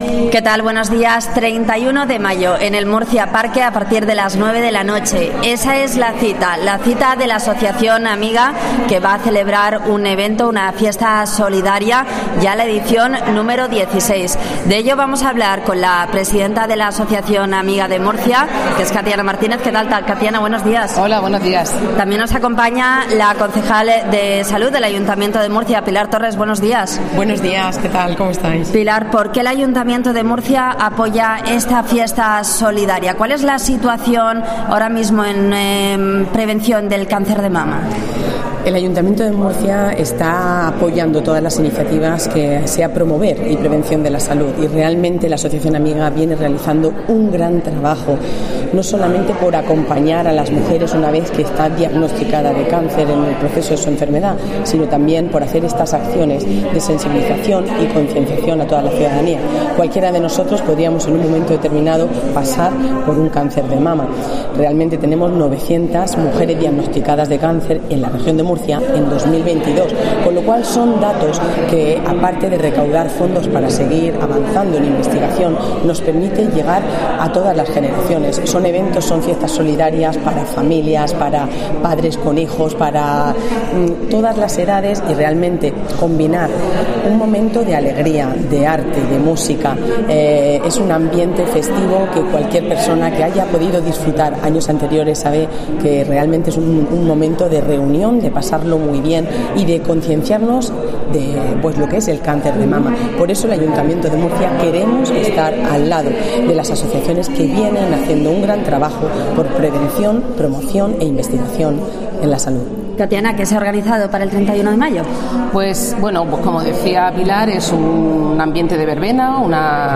Asociación Amiga y la concejal de Sanidad explican en COPE como va a ser la fiesta solidaria
REPORTAJE